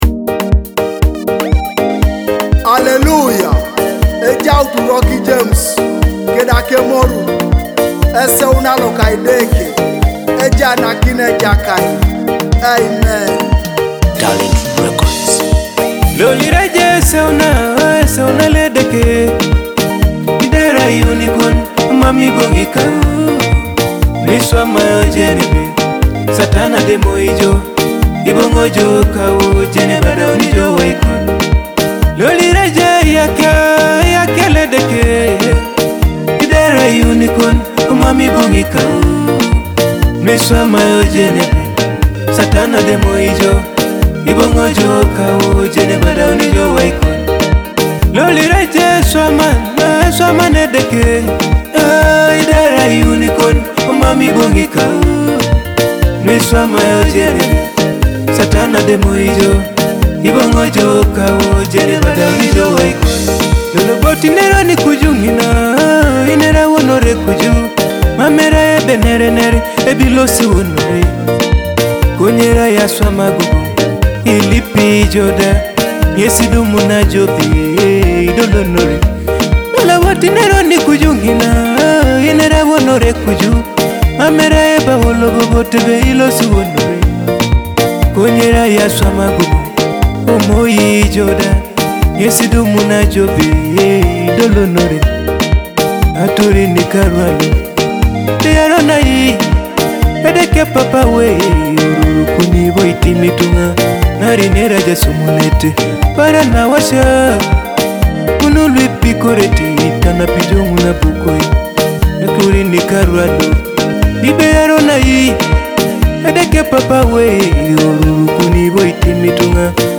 gospel song